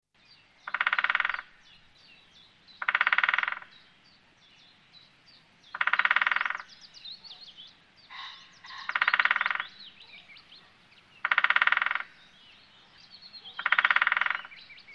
Grote bonte specht
Vaak kun je de Grote bonte specht in de stadsbossen horen en zien…!
Grote-bonte-specht-1.mp3